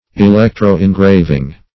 Search Result for " electro-engraving" : The Collaborative International Dictionary of English v.0.48: Electro-engraving \E*lec`tro-en*grav"ing\, n. The art or process of engraving by means of electricity.